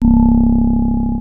HOHNER 1982 1.wav